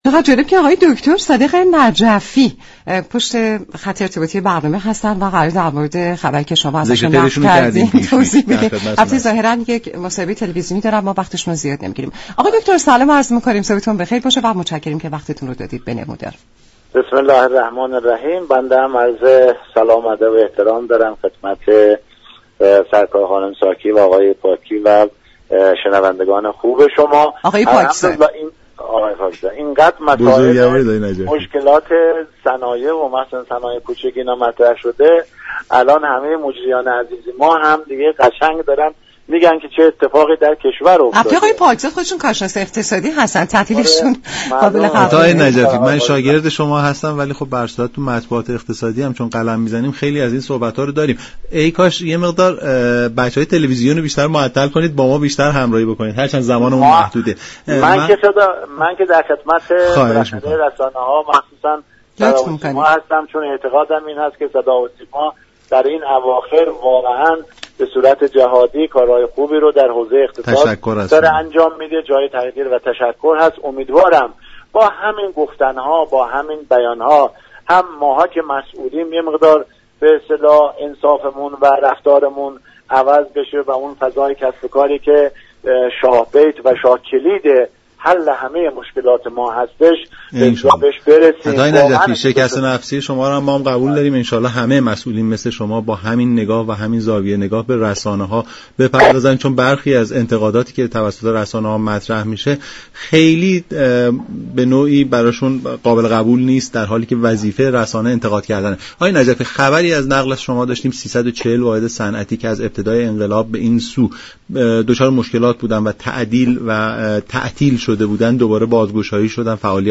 دكتر صادق نجفی معاون وزیر صنعت، معدن و تجارت و رئیس سازمان صنایع كوچك و شهرك های صنعتی در گفت و گو با برنامه «نمودار» رادیو ایران گفت